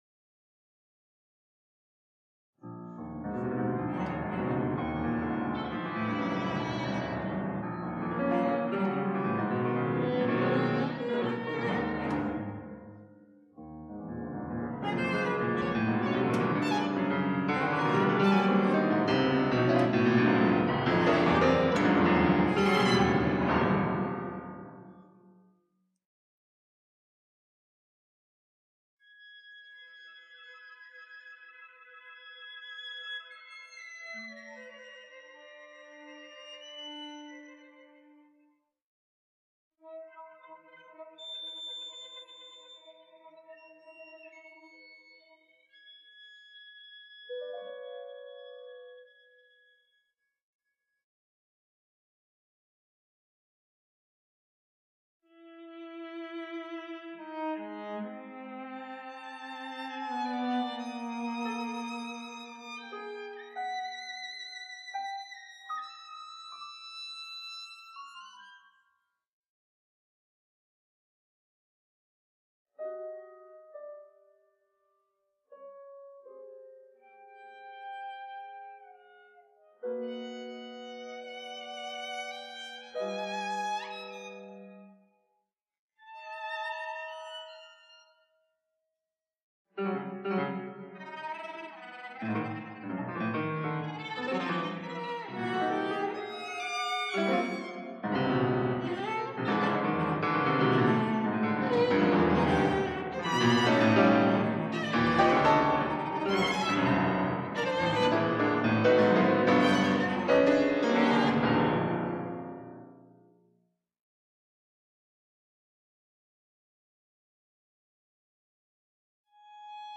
per violino, violoncello e pianoforte